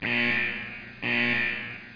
otheralarm.mp3